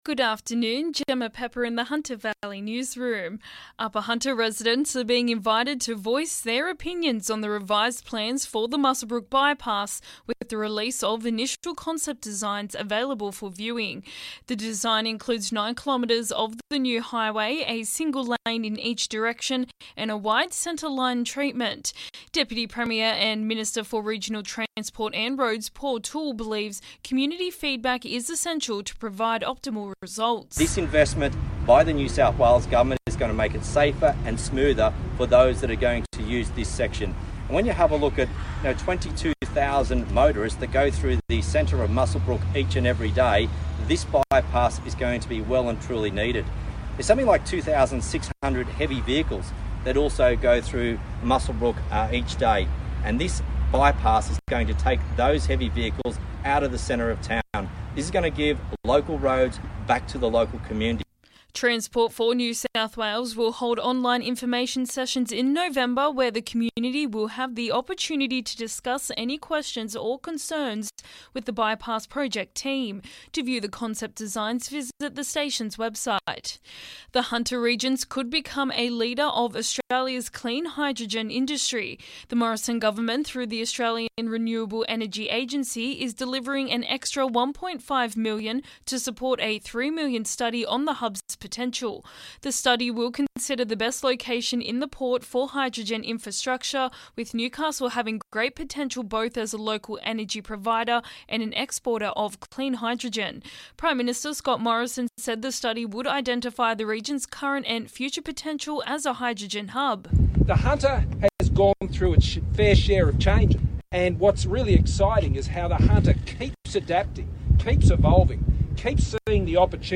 LISTEN: Hunter Valley Local News Headlines 08/11/21